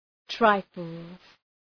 {‘traıfəlz}
trifles.mp3